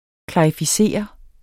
Udtale [ klɑifiˈseˀʌ ]